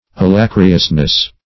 Alacriousness \A*lac"ri*ous*ness\, n.